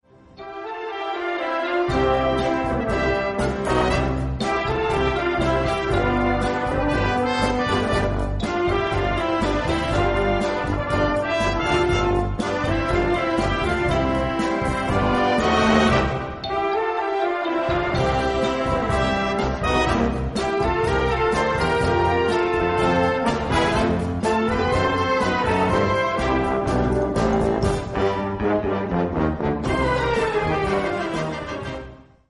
Gattung: Moderner Einzeltitel
A4 Besetzung: Blasorchester Zu hören auf